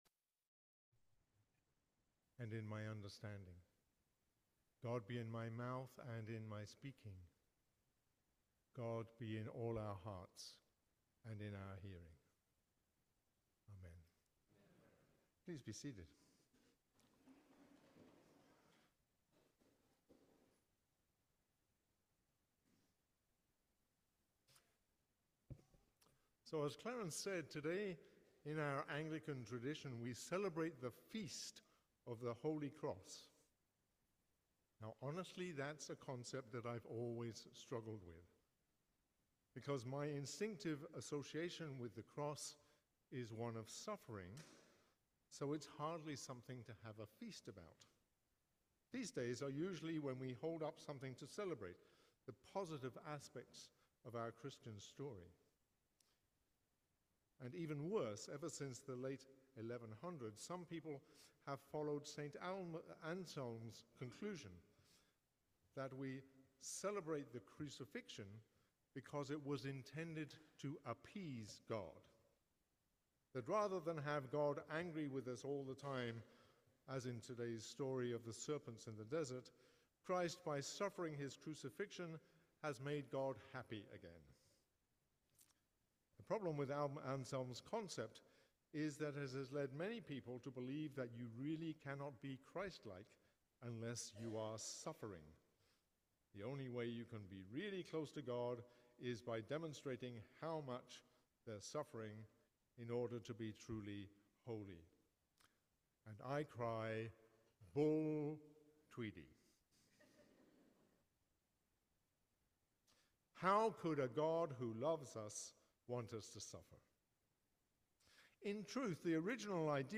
Sermon on the Second Sunday of Creation | Feast of the Holy Cross